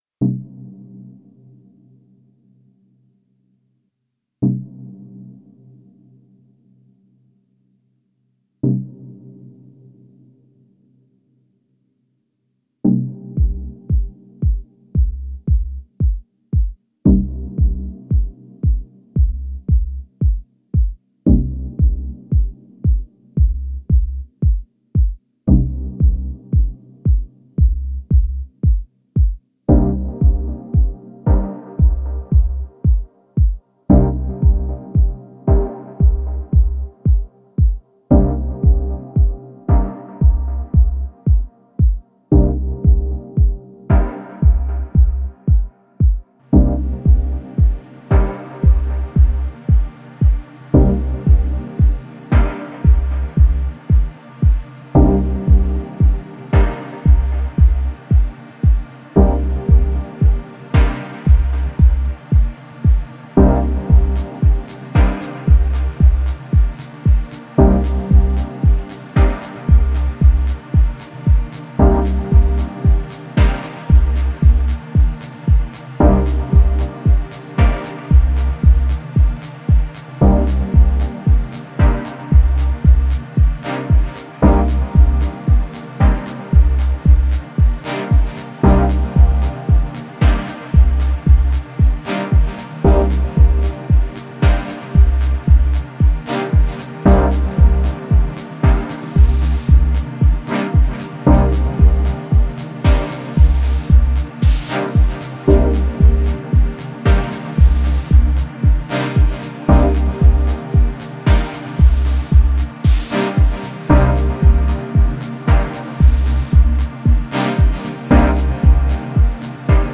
Genre: Dub Techno/Deep House.